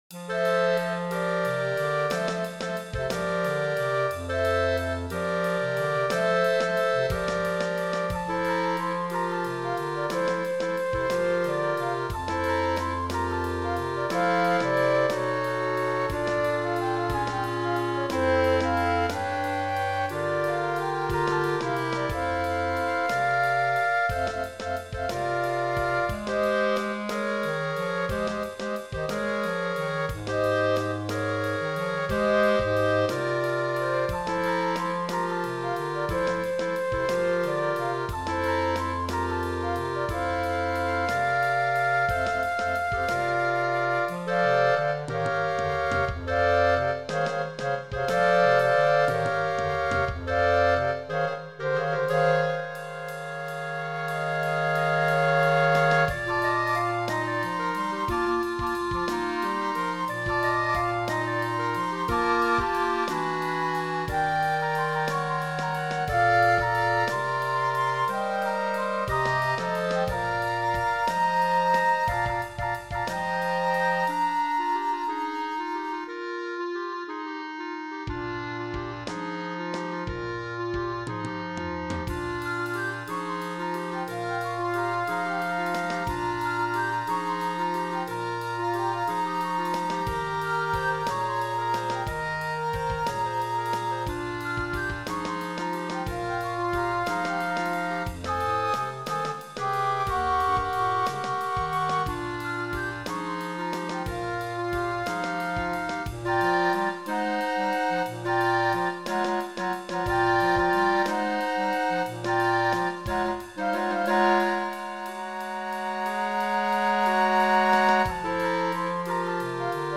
2 Flutes, 2 Clarinets, Bass Clarinet